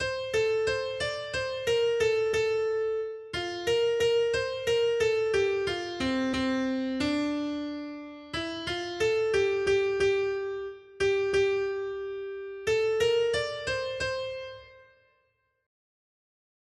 Noty Štítky, zpěvníky ol52.pdf responsoriální žalm Žaltář (Olejník) 52 Ž 147, 12-15 Ž 147, 19-20 Skrýt akordy R: Slovo se stalo tělem a přebývalo mezi námi. 1.